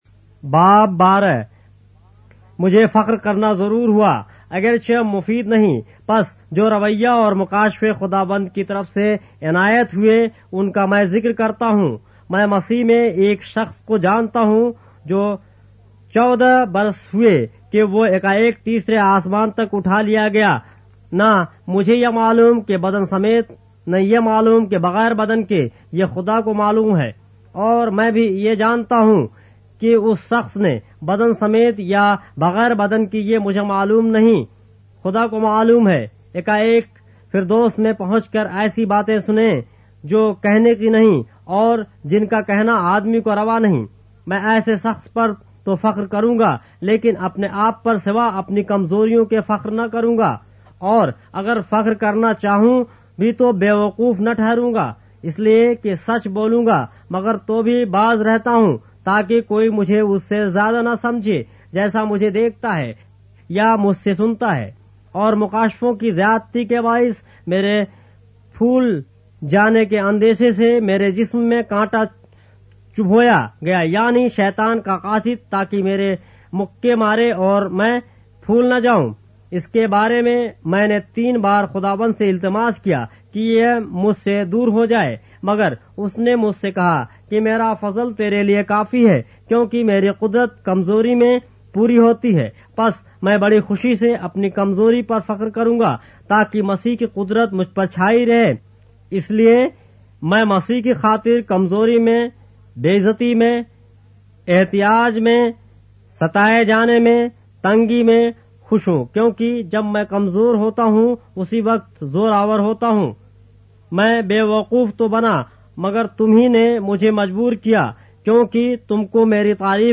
اردو بائبل کے باب - آڈیو روایت کے ساتھ - 2 Corinthians, chapter 12 of the Holy Bible in Urdu